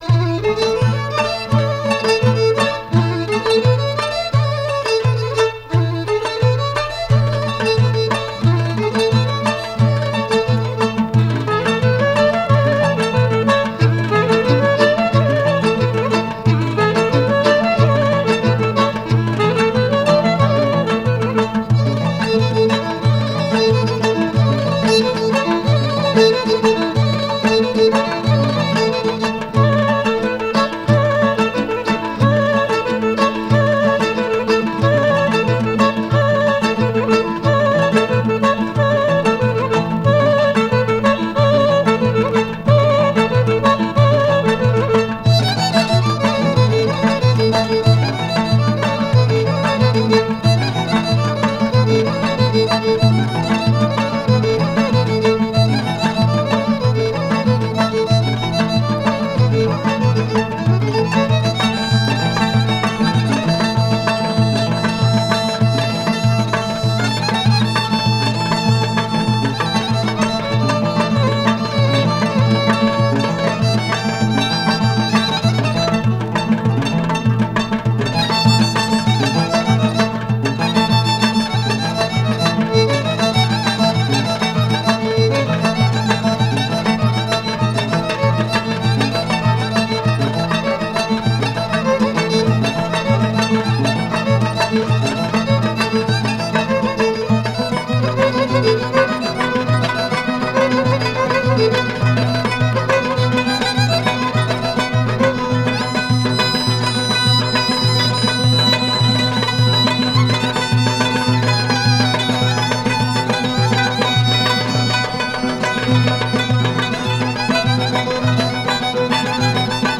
Genre: National Folk